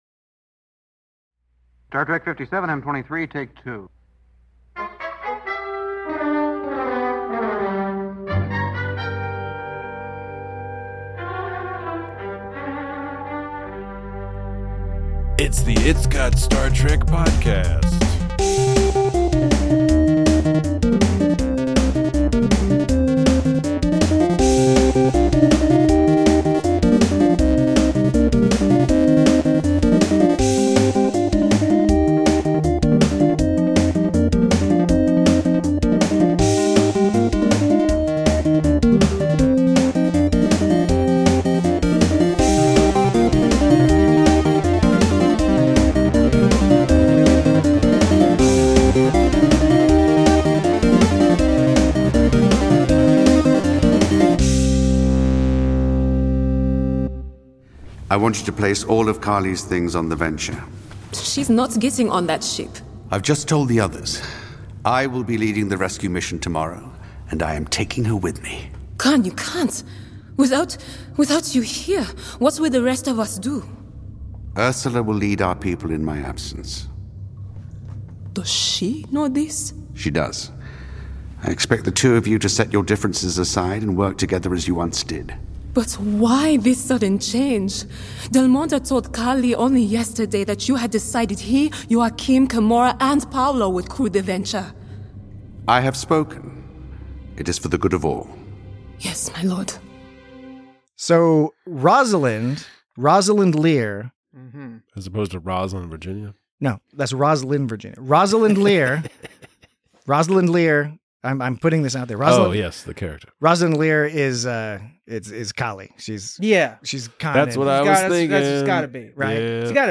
Join your Shakespearean hosts as they discuss the increasingly intense machinations and manipulations of Khan Noonien-Singh in the penultimate episode of his titular podcast.